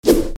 s_flap.mp3